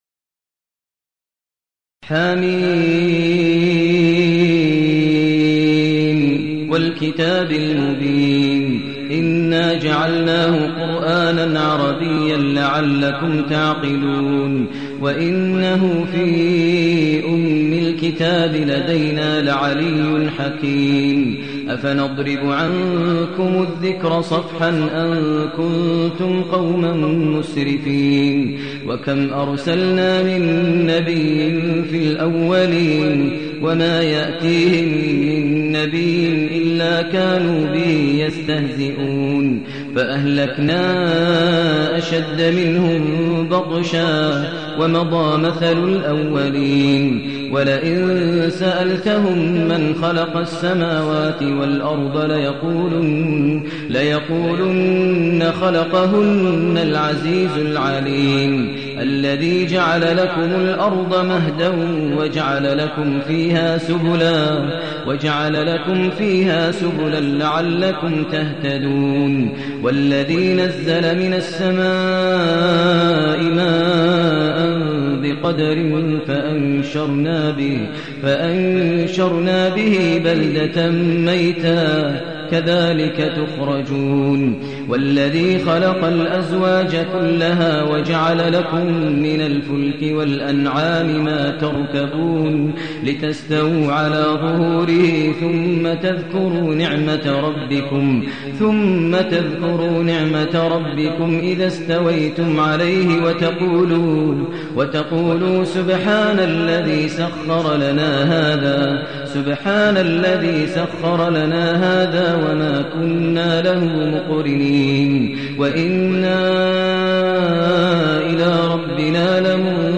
المكان: المسجد الحرام الشيخ: فضيلة الشيخ ماهر المعيقلي فضيلة الشيخ ماهر المعيقلي الزخرف